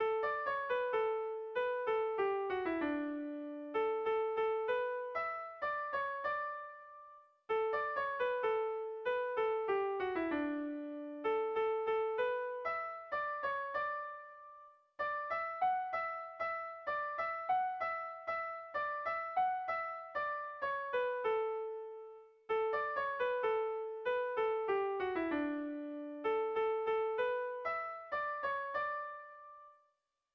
Kontakizunezkoa
Zortziko handia (hg) / Lau puntuko handia (ip)
AABA